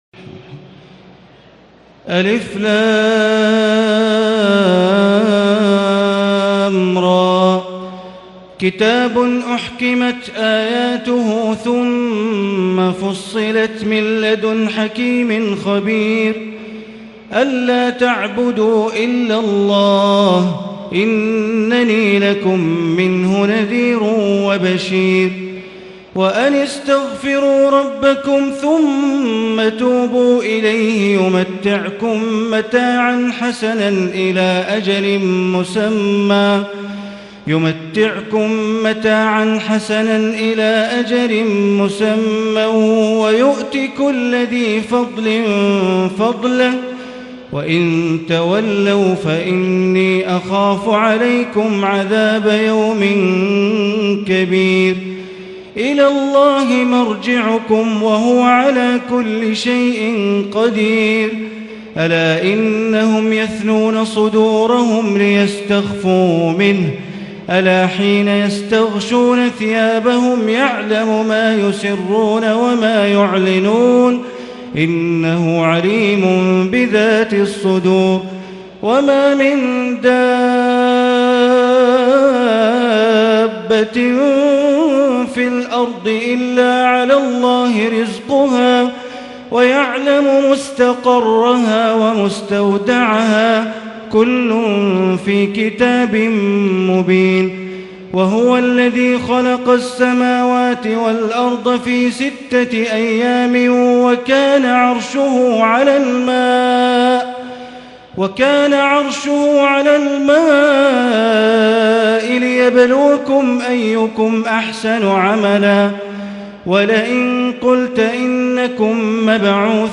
تراويح الليلة الحادية عشر رمضان 1440هـ من سورة هود (1-83) Taraweeh 11 st night Ramadan 1440H from Surah Hud > تراويح الحرم المكي عام 1440 🕋 > التراويح - تلاوات الحرمين